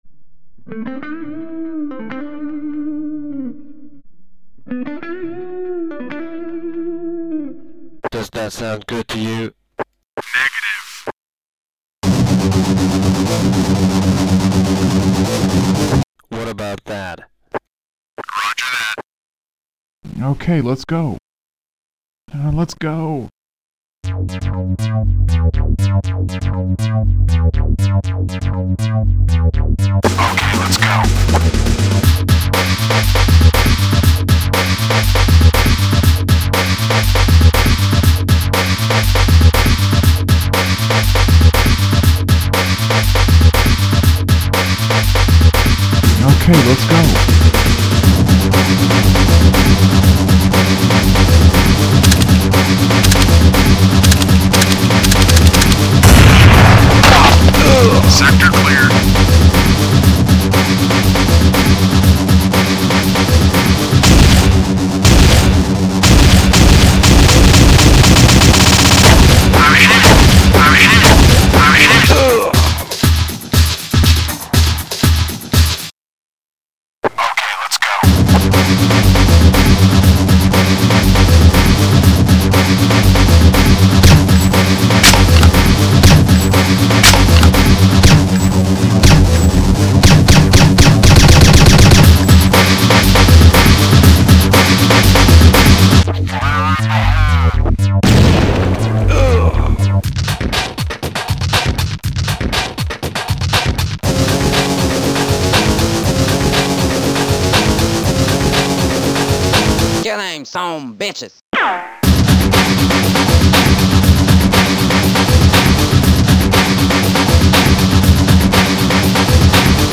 cs_-_counter_strike_rock.mp3